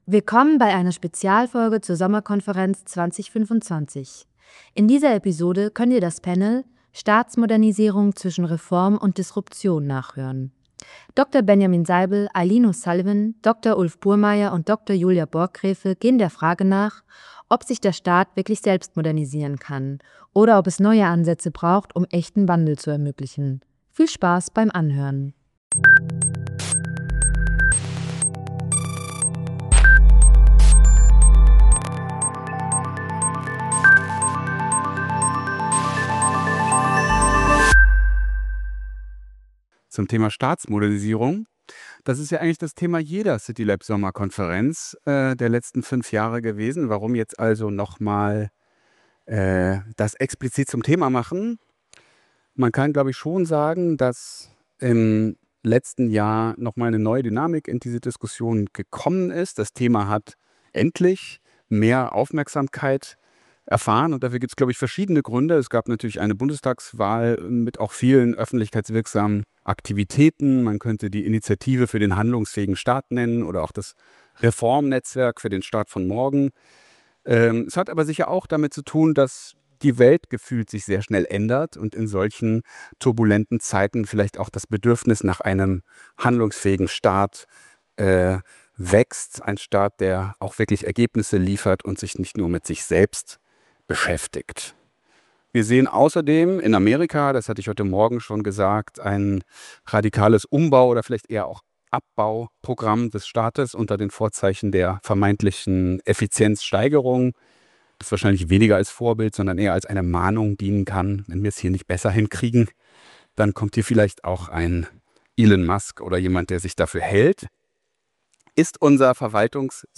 in dieser Spezialfolge zur CityLAB Sommerkonferenz 2025.